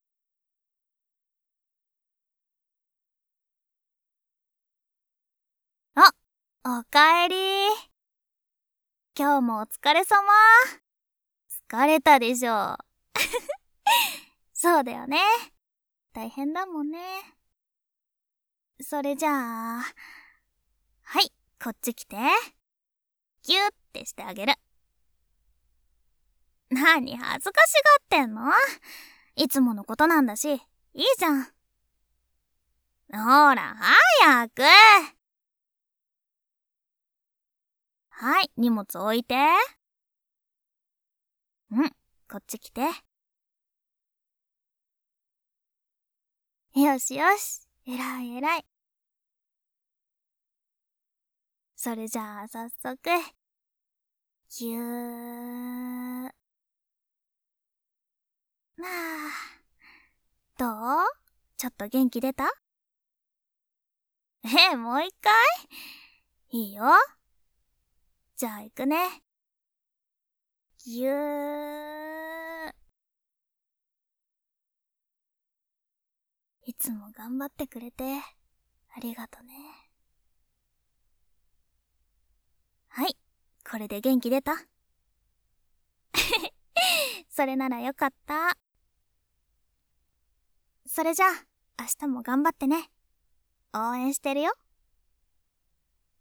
纯爱/甜蜜 日常/生活 温馨 萌 健全 治愈 皆大欢喜 催眠音声